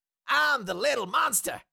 Cartoon Little Monster, Voice, I Am The Little Monster Sound Effect Download | Gfx Sounds
Cartoon-little-monster-voice-i-am-the-little-monster.mp3